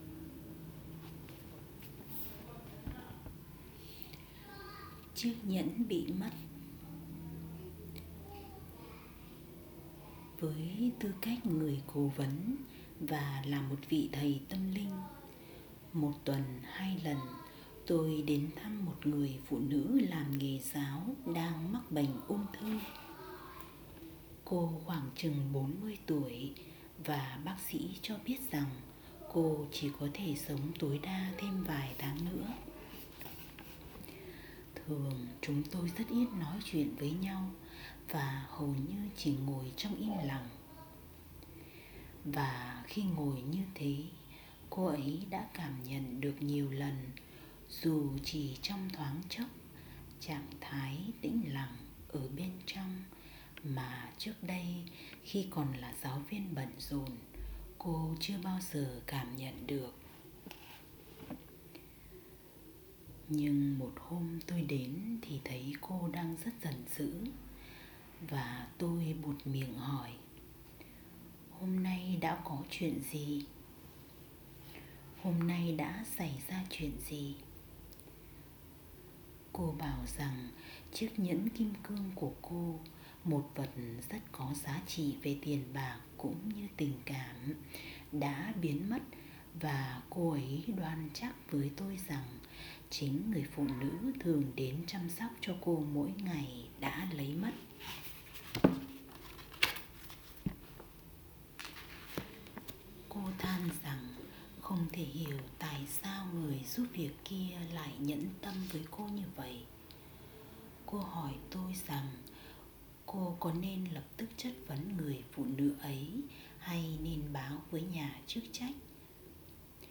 Câu chuyện ‘Chiếc nhẫn bị mất’, trích đọc từ A New Earth – Thức tỉnh Mục đích sống, tác giả Eckhart Tolle